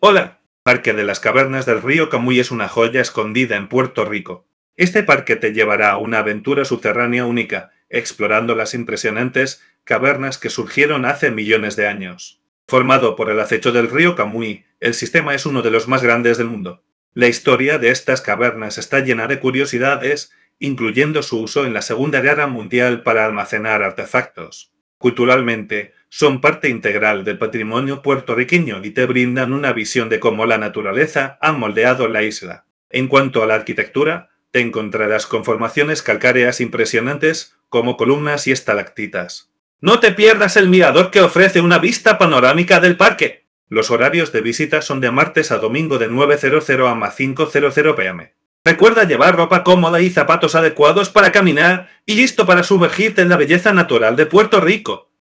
karibeo_api / tts / cache / b451c4222ad520f940c0479e9772ae82.wav